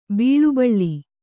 ♪ bīḷu baḷḷi